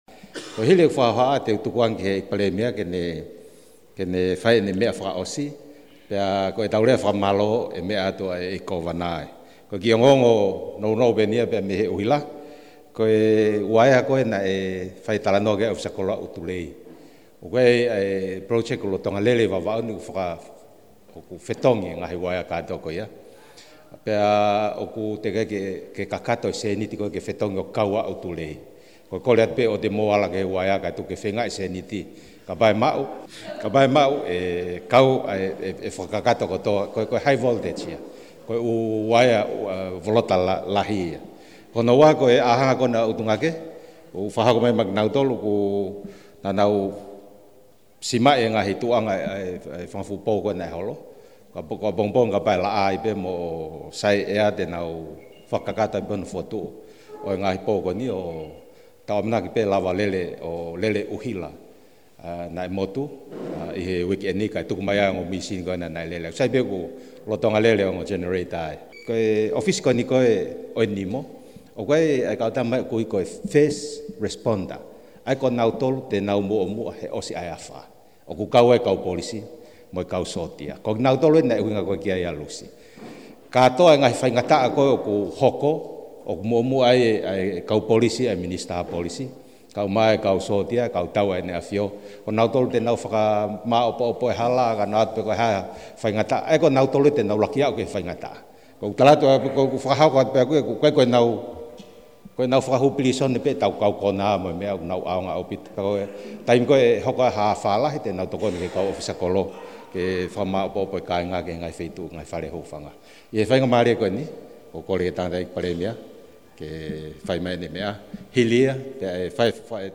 An audio of the Prime Minister’s meeting in Vava’u was provided by his office and transcribed and translated into English by Kaniva News.